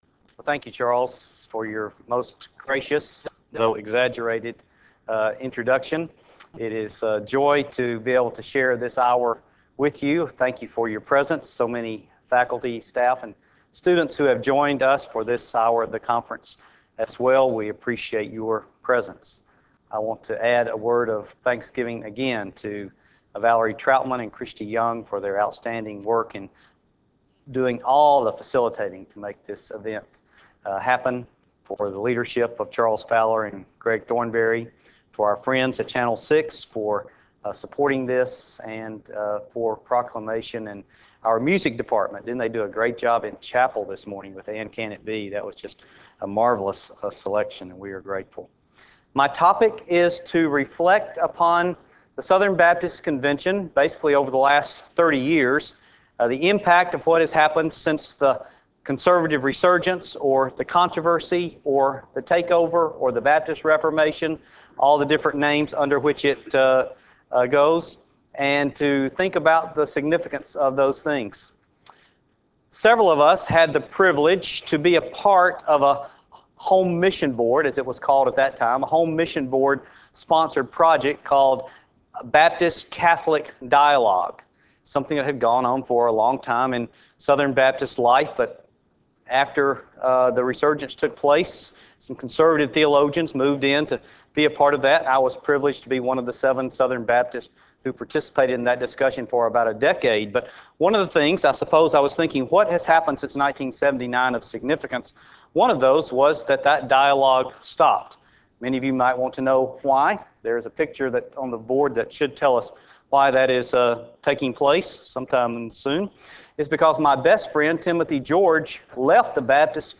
Union University Address: The Southern Baptist Convention Since 1979 Recording Date